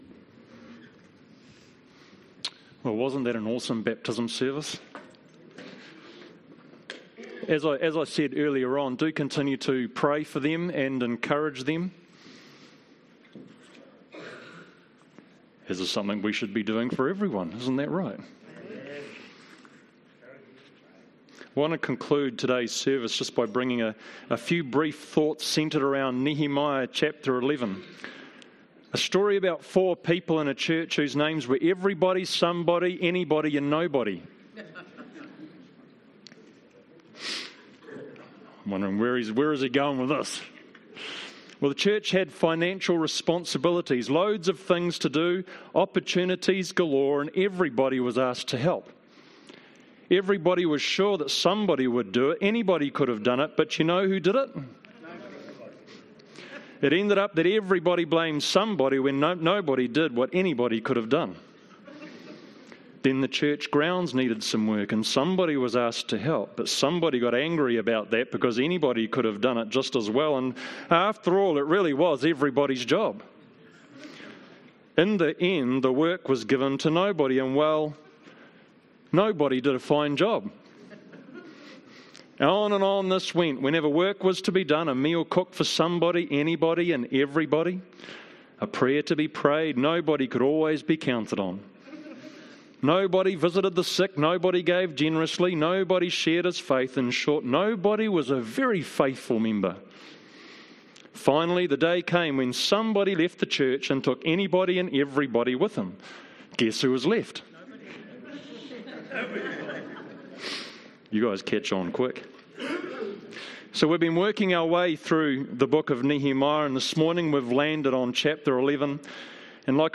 I want to conclude our service today by bringing a few brief thoughts centred around Nehemiah 11… (Illustration) A story about four people in a church whose names were Everybody, Somebody, Anybody and Nobody.